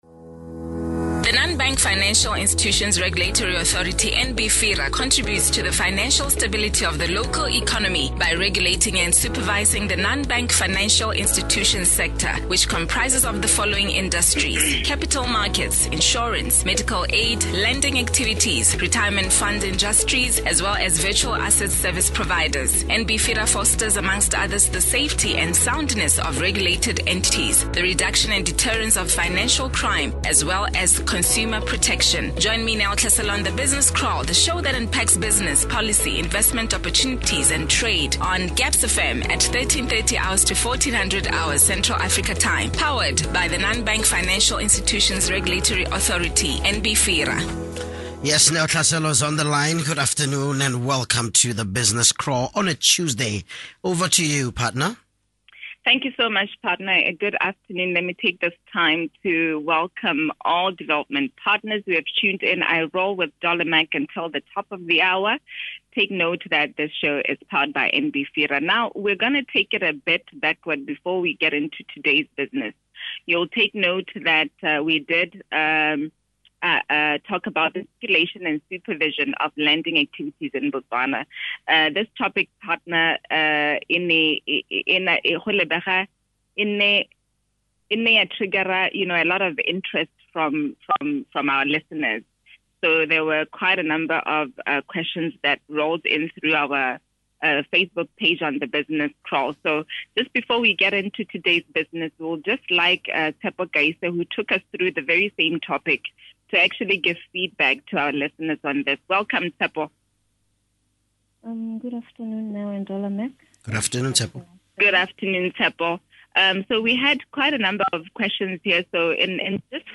The interview unpacks the role of NBFIRA in Anti-Money Laundering, Combating Terrorism Financing & Proliferation. The interview session amongst other topics, discusses why customers are regularly required to submit their updated information to regulated entities and the regulated entities' obligation to comply with AML/CFT&P requirements. audio: NBFIRA BUSINESS KRAAL 28 JUNE 2022 (1).mp3